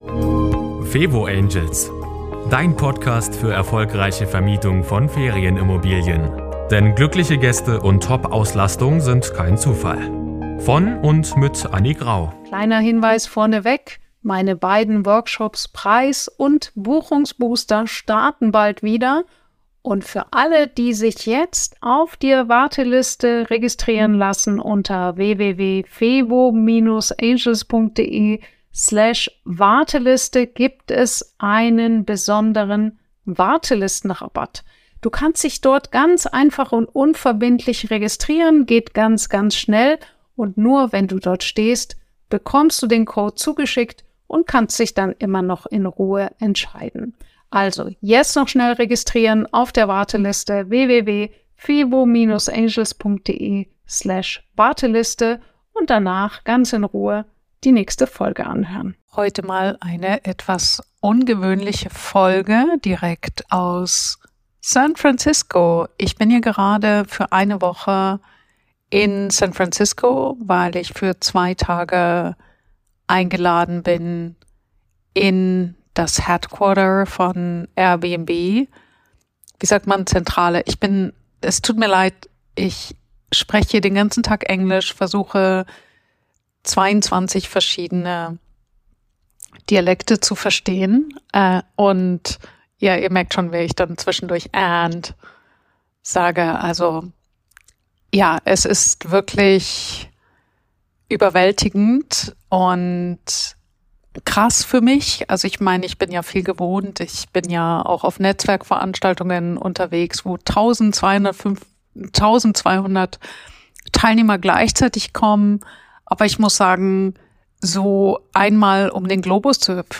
#131 Live aus San Francisco